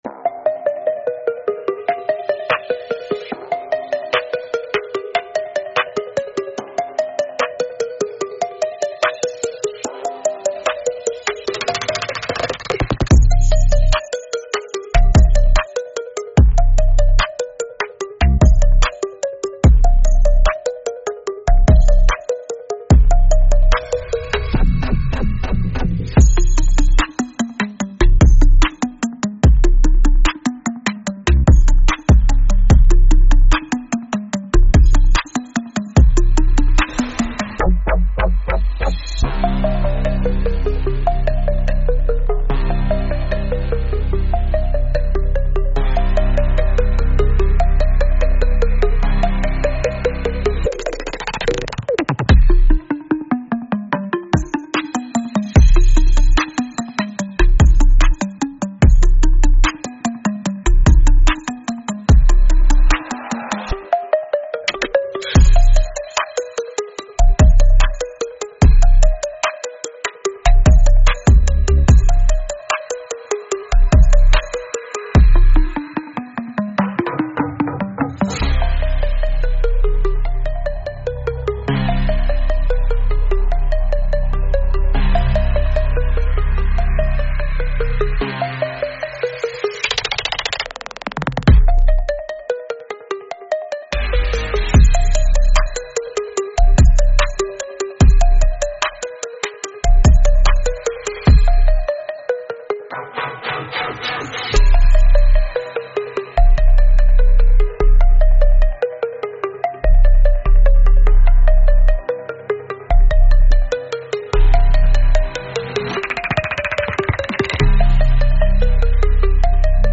Instrumental - Real Liberty Media DOT xyz - Grimnir Radio